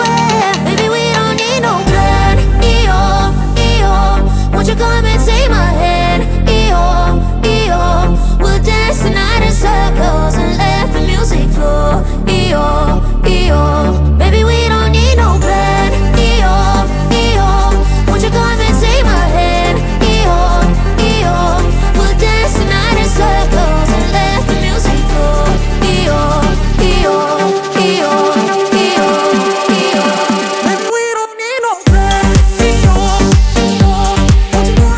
Жанр: Африканская музыка
# Afro House